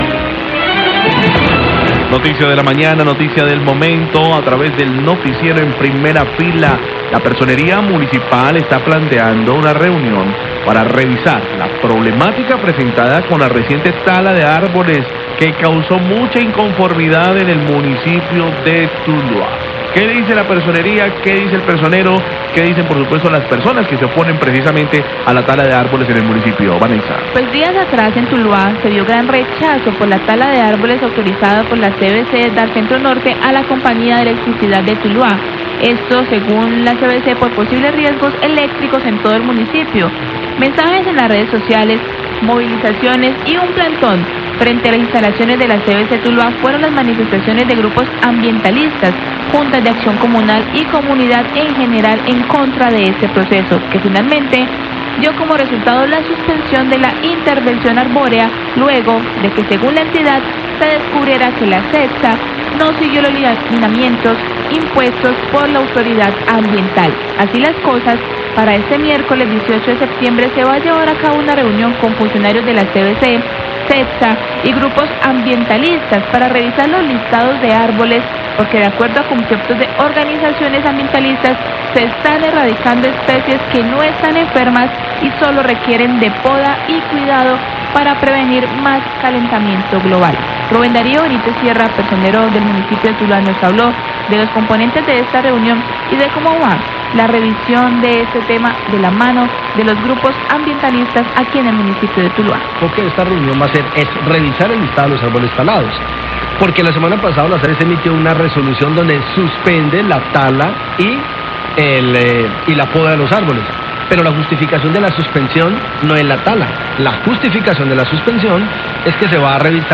Radio
Personero de Tuluá se pronunció sobre tema de tala de árboles y los componentes de esta reunión.